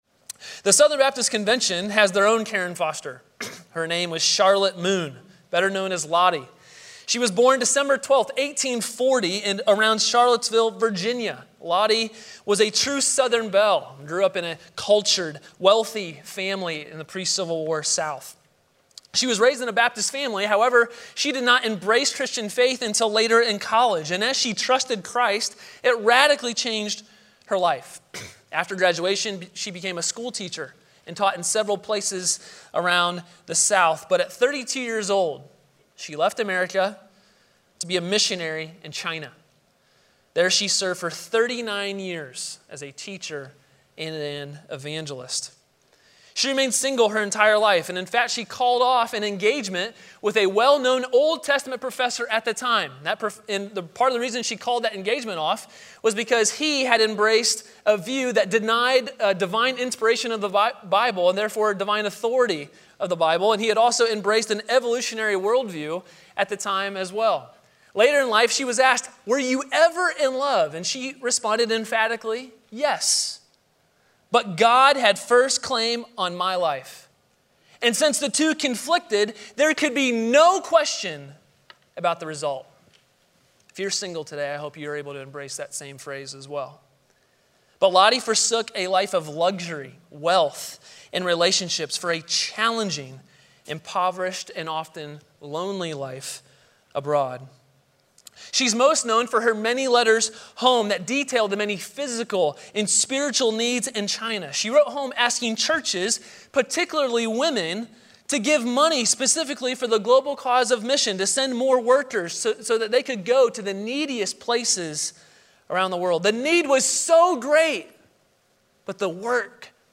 A sermon from the series "The Peace of Christmas." In Christ we anticipate a day of global, total, and eternal peace.